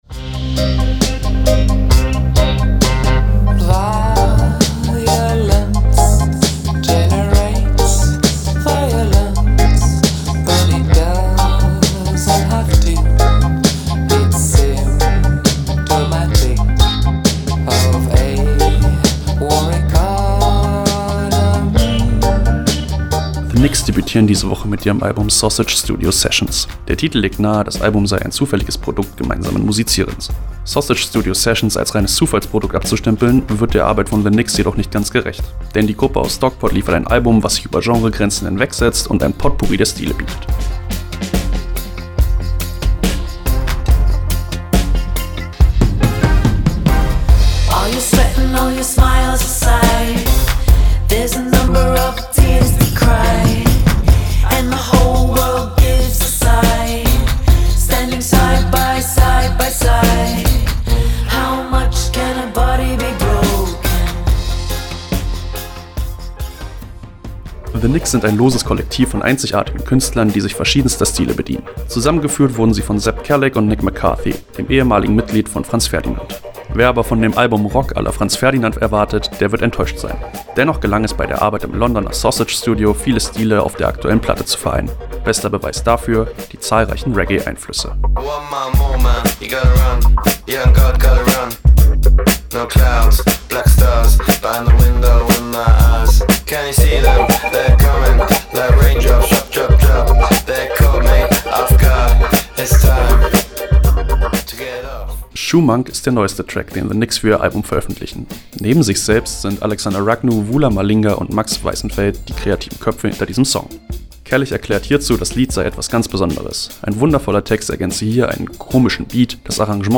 Bester Beweis dafür: die zahlreichen Reggae Einflüsse.
Das Arrangement klingt dementsprechend artsy.